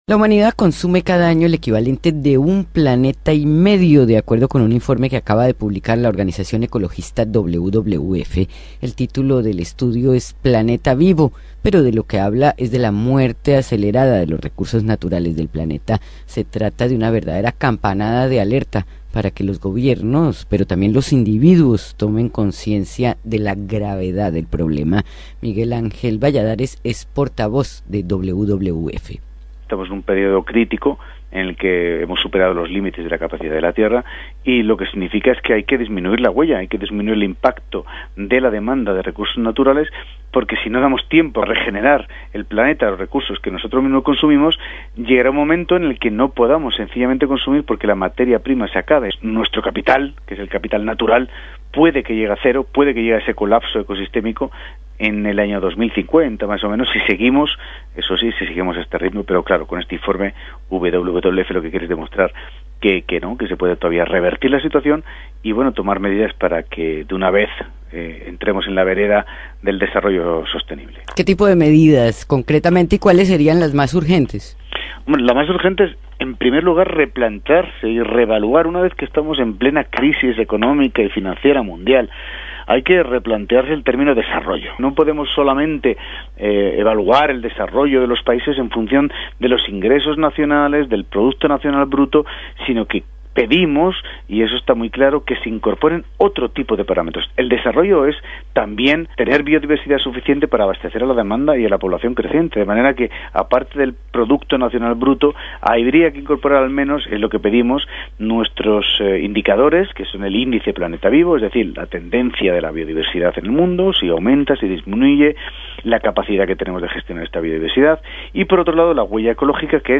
La humanidad consume cada año el equivalente de un planeta y medio, de acuerdo al estudio "Planeta vivo", publicado por la organización ecologista WWF, en lo que se presenta como un llamado de alerta para que los gobiernos y los individuos tomen conciencia de la gravedad del problema. Escuche el informe de Radio Francia Internacional.